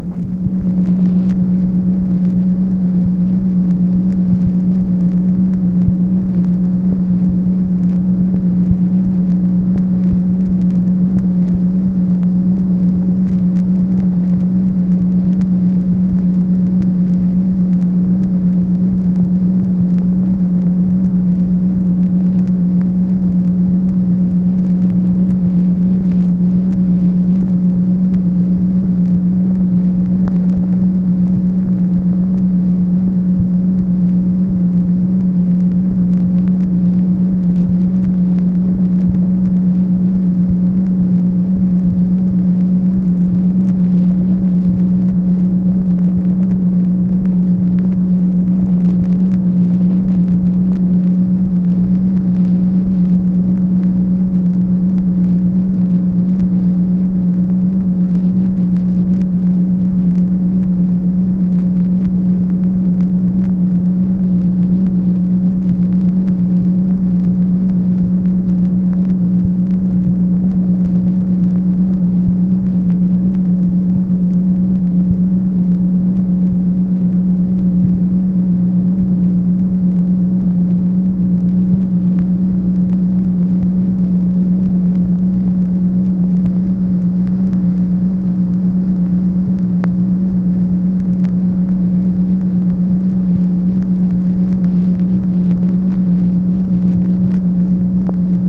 MACHINE NOISE, May 20, 1965
Secret White House Tapes | Lyndon B. Johnson Presidency